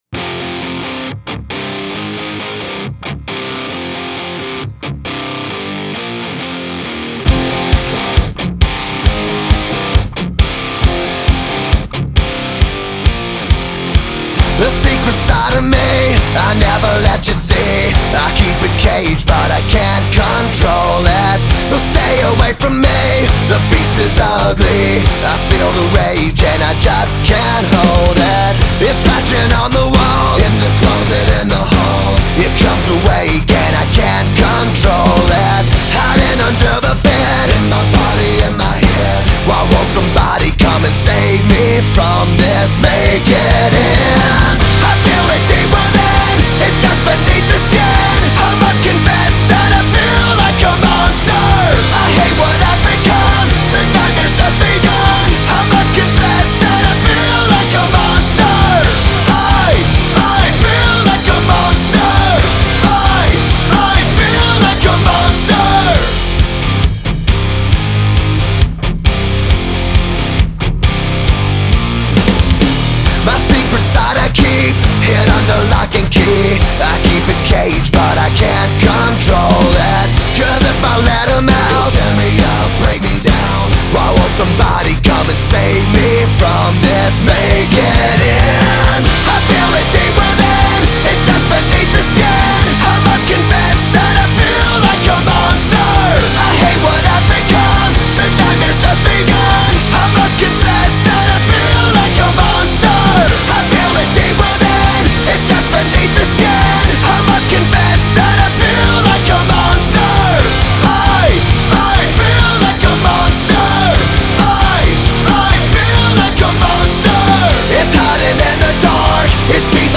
Hard Rock
rock music